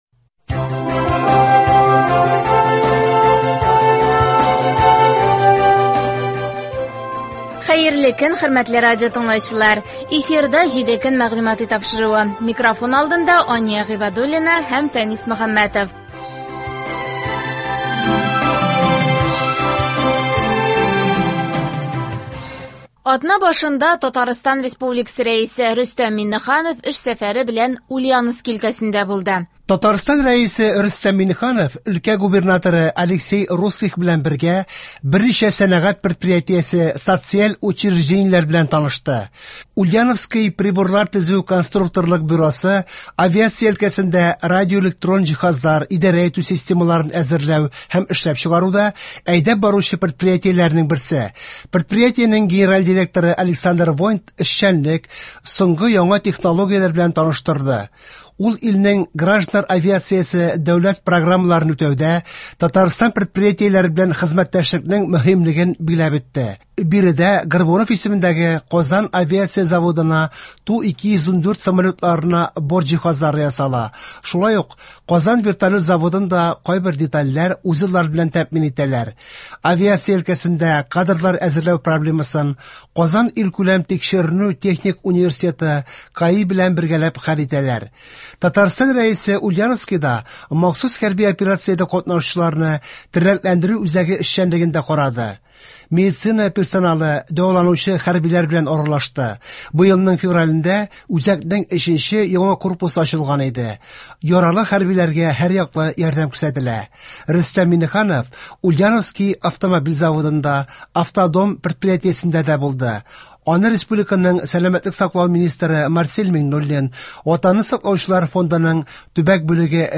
Атналык күзәтү.